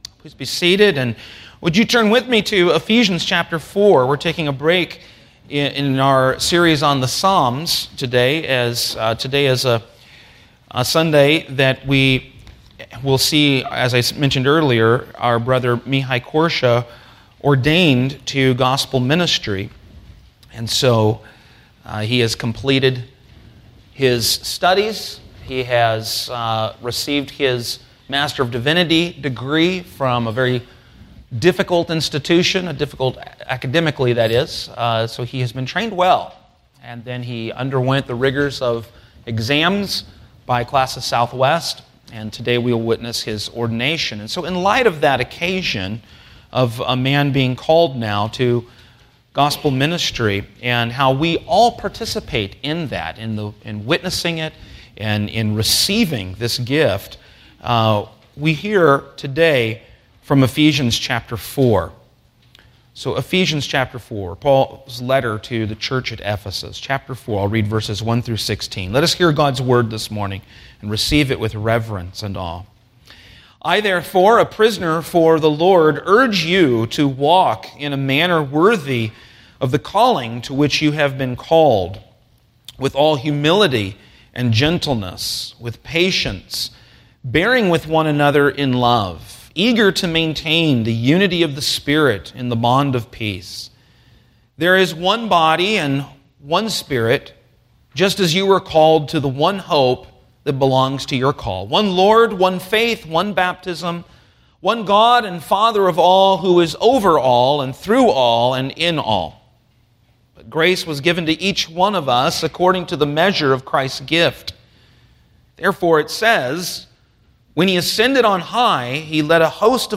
Sermons — Christ United Reformed Church